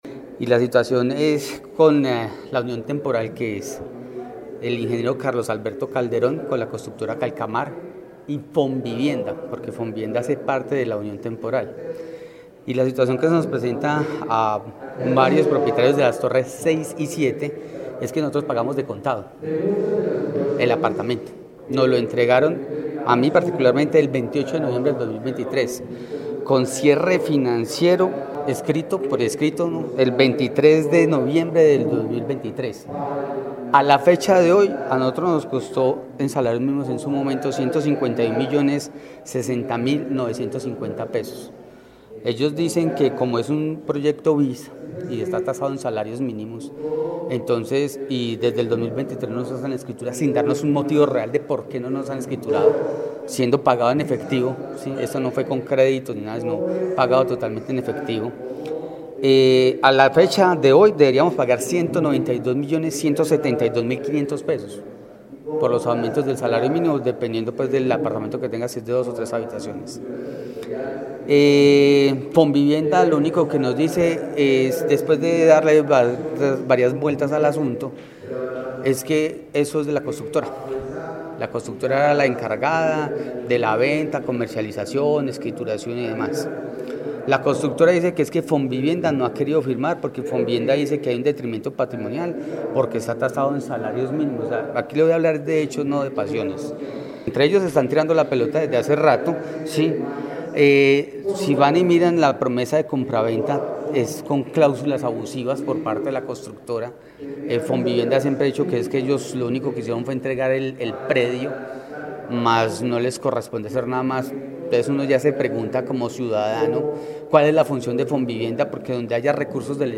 Afectado